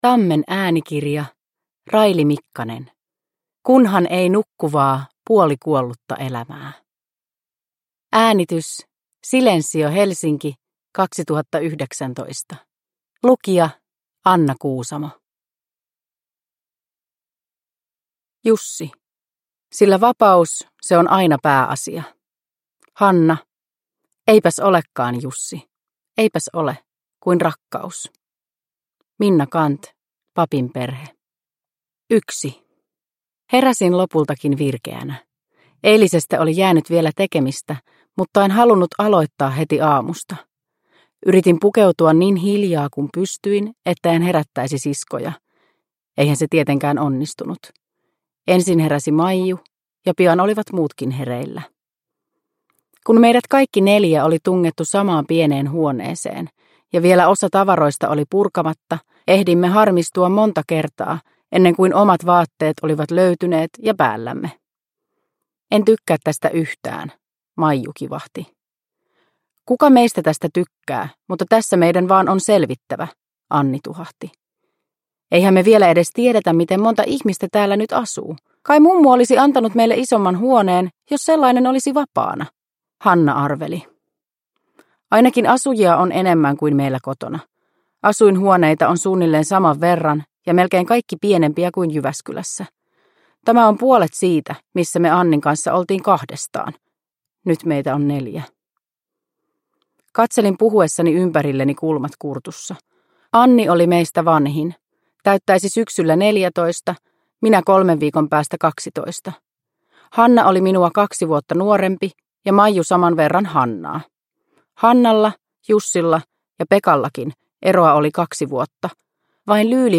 Kunhan ei nukkuvaa puolikuollutta elämää – Ljudbok – Laddas ner